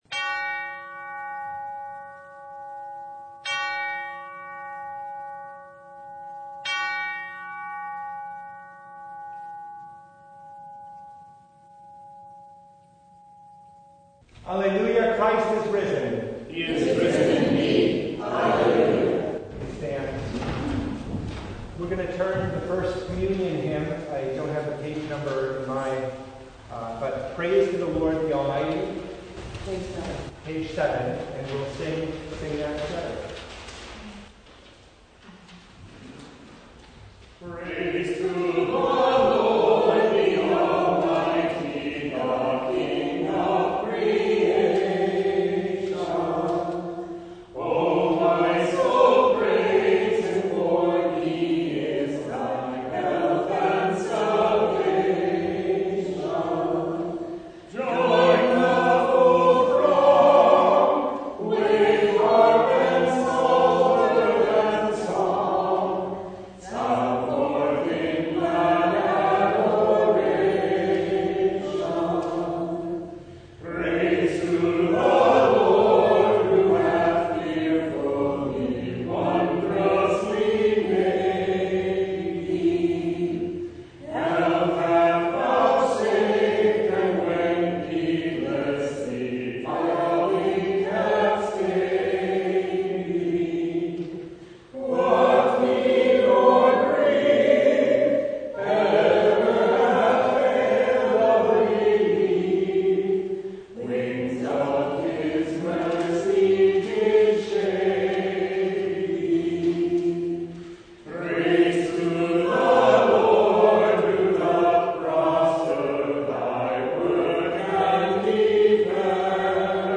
The Fifth Sunday of Easter (2023) – Evening Service
Full Service